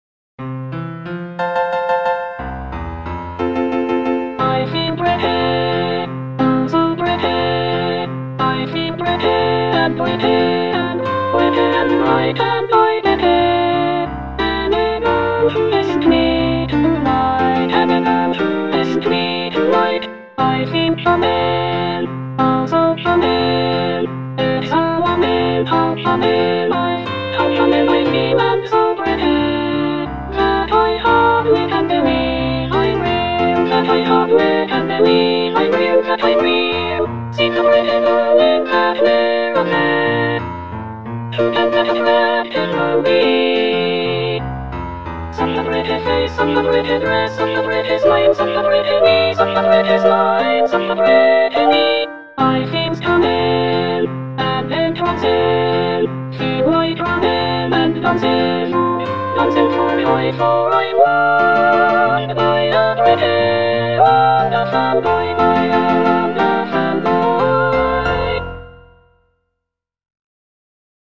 CHORAL SELECTION
(All voices)